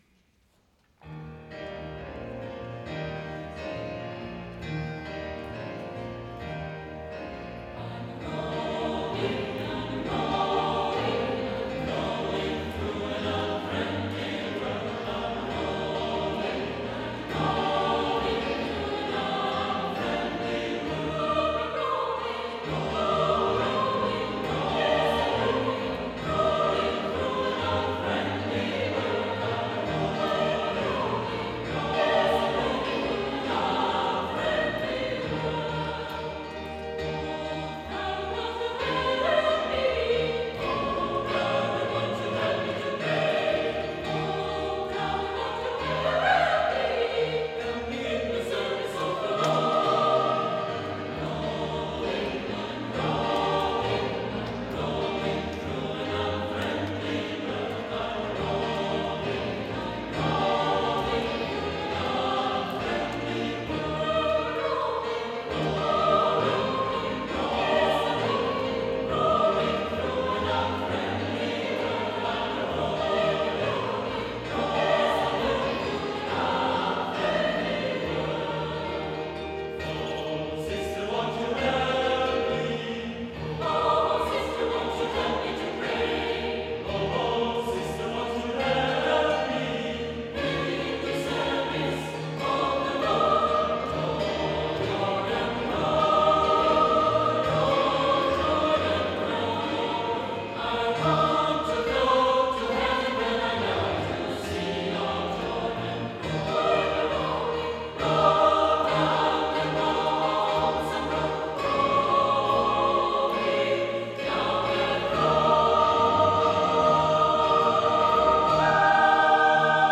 • When the saints go marching in … spirituály známé i neznámé – 20. koncert z cyklu Fakulta v srdci Karlova
živá nahrávka z koncertu výběr